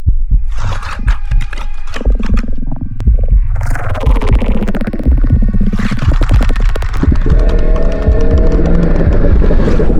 Звук коллапса аномалии плоти